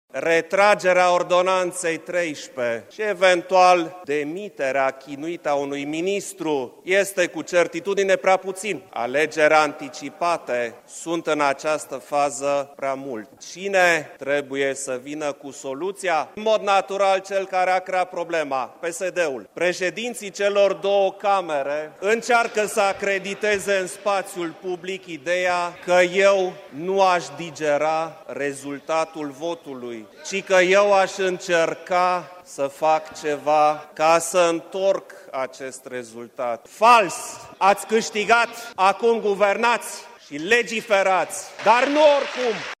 Preşedintele Klaus Iohannis a susţinut, astăzi, un discurs în plenul Parlamentului în care i-a cerut Guvernului să conducă responsabil, predictibil şi transparent.